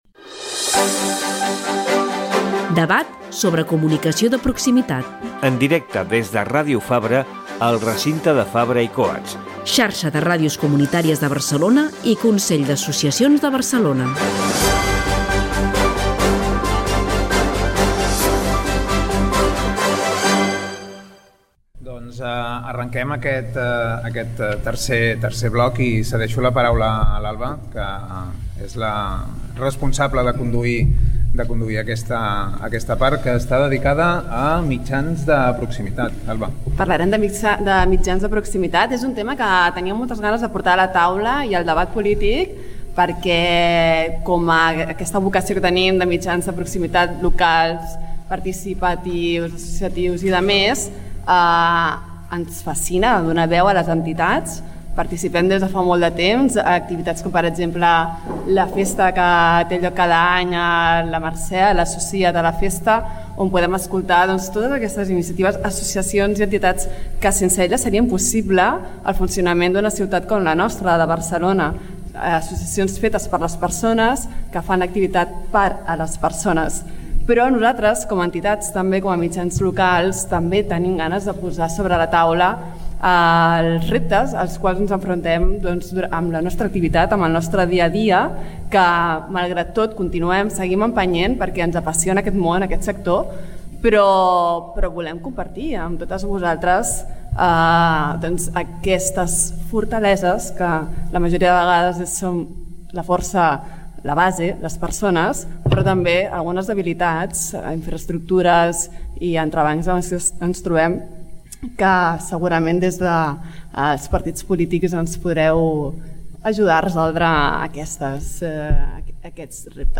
Careta del programa, debat sobre comunicació de proximitat a Barcelona, organitzat per la Xarxa de Ràdios Comunitàries de Barcelona i el Consell d’Associacions de Barcelona amb motiu de les eleccions municipals 2023 Gènere radiofònic Informatiu